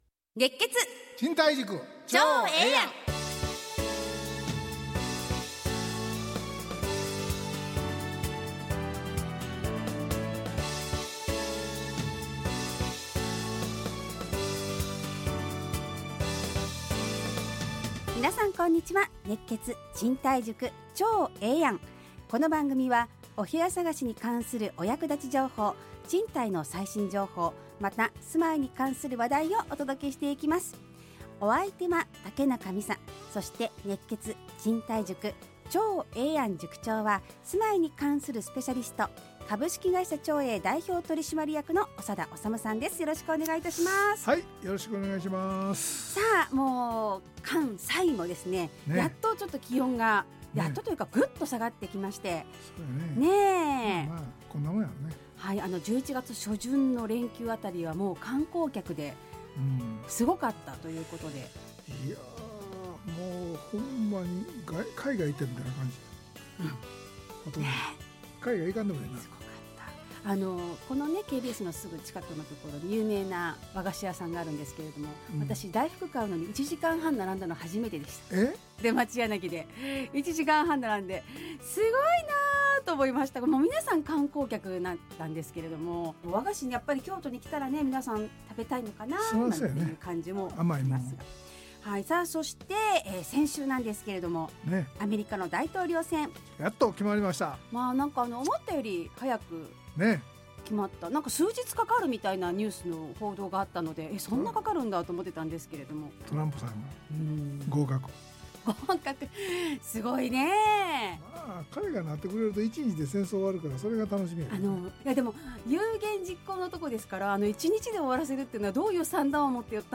ラジオ放送 2024-11-15 熱血！